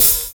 DISCO 2 OH.wav